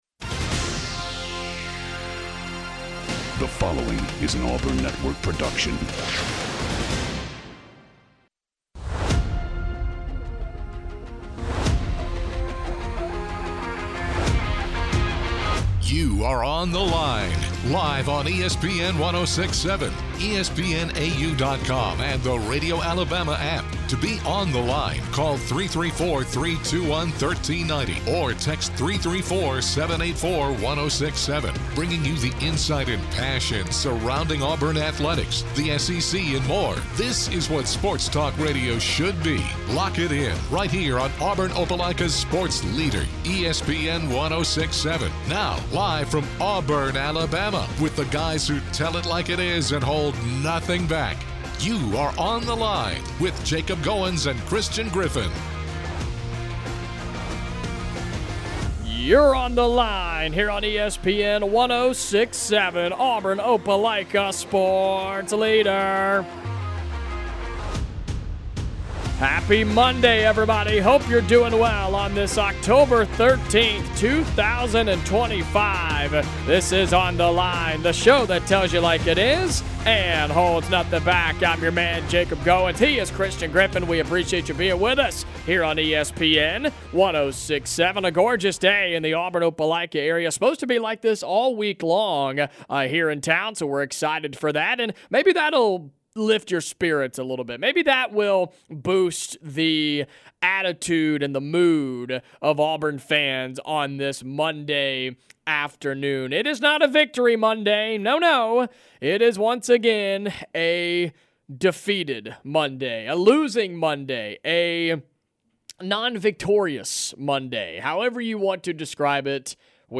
The guys take dozens of phone calls and texts from fans who are fed up with the Auburn Football program.